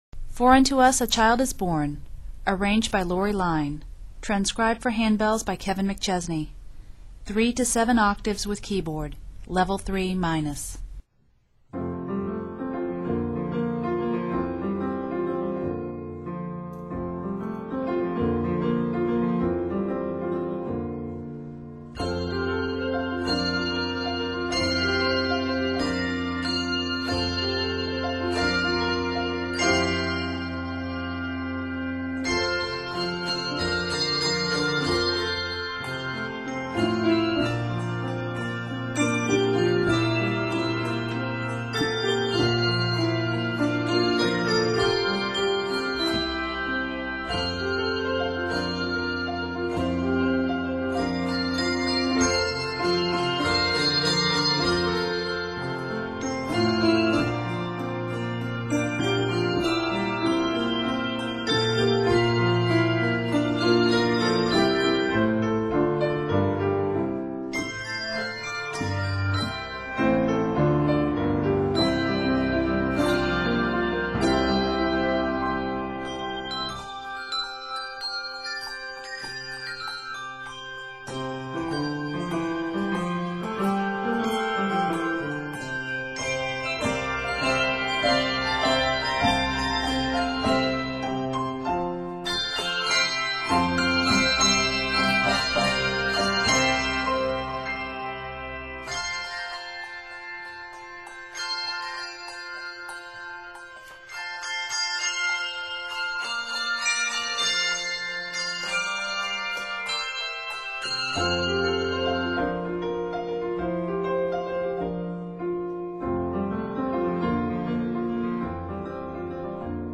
for 3-7 octaves and keyboard